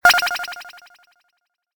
startbleep.ogg